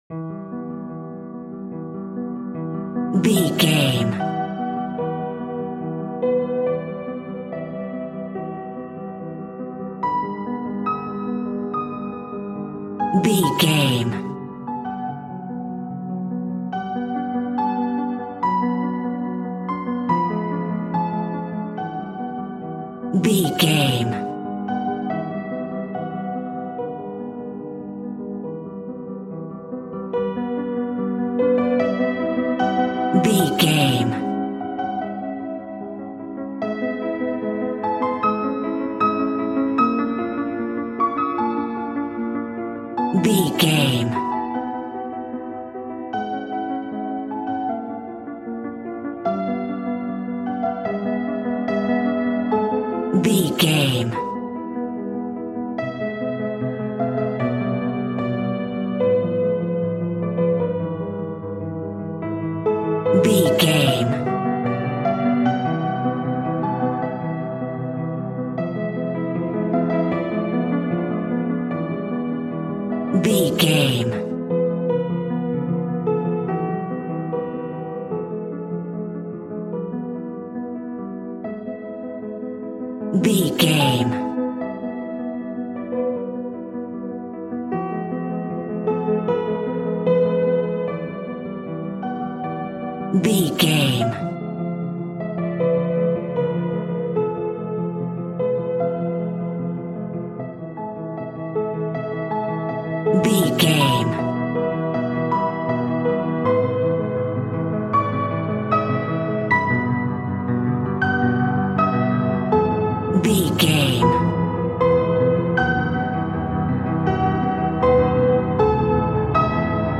Aeolian/Minor
ominous
haunting
eerie
instrumentals
horror music